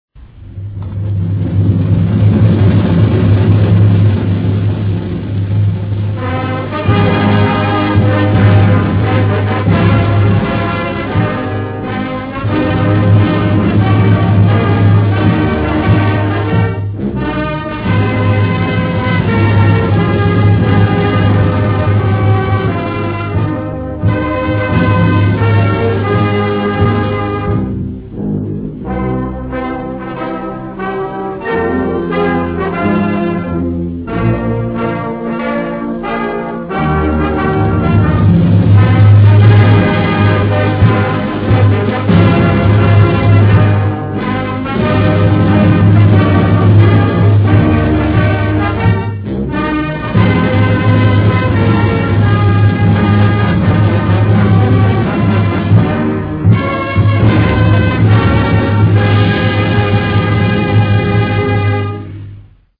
국가 음성 클립